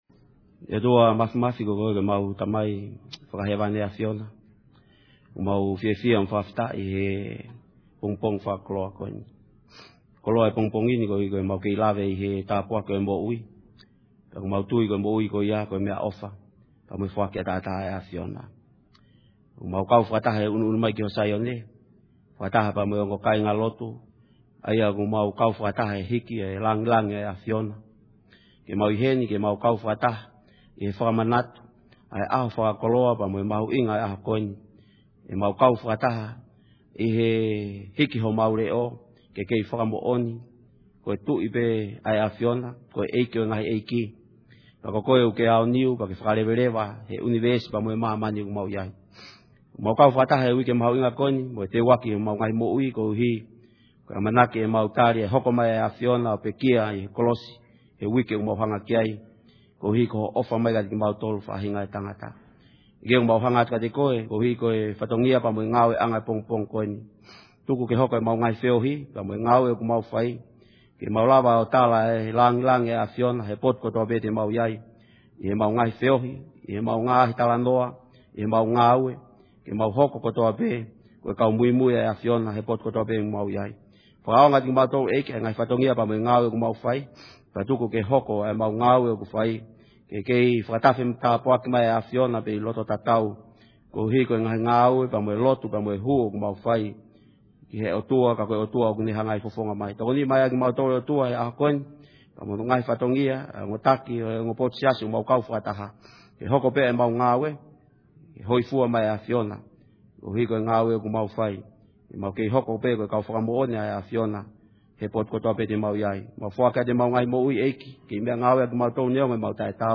Palm Sunday Worship Service
Palm Sunday Prayer